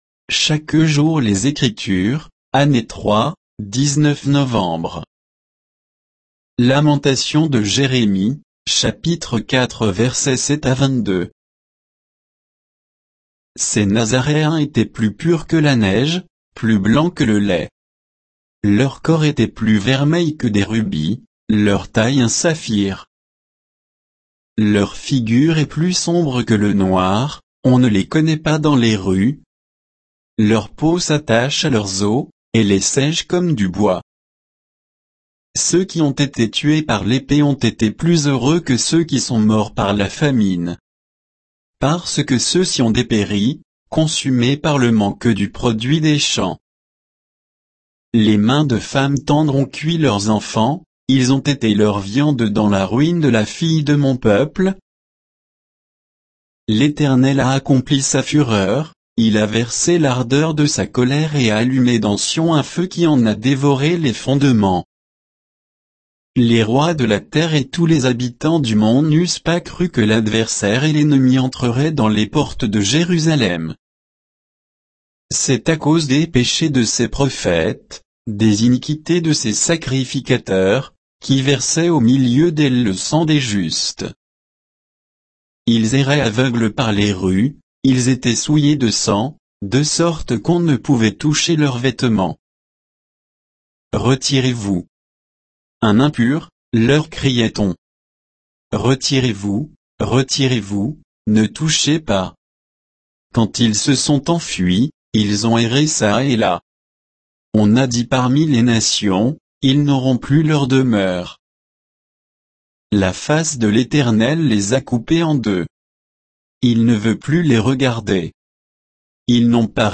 Méditation quoditienne de Chaque jour les Écritures sur Lamentations de Jérémie 4